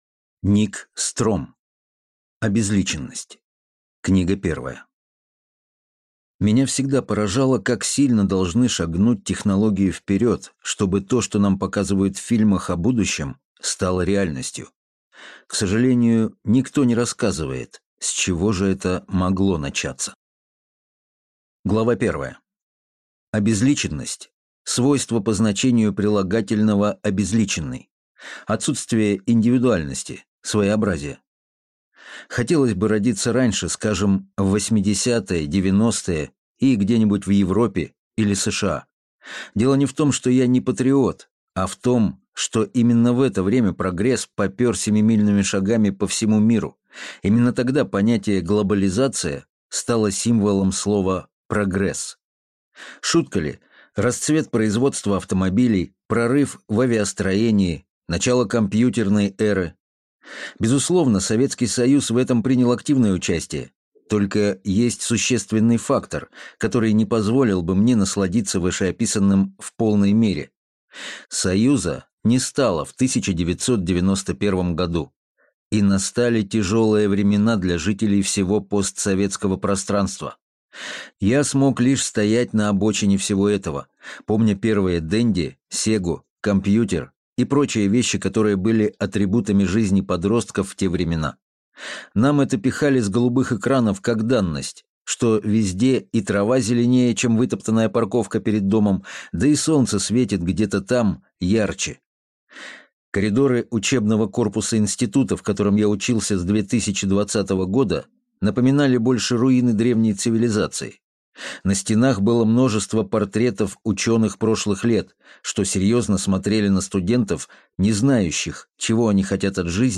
Аудиокнига Обезличенность. Книга первая | Библиотека аудиокниг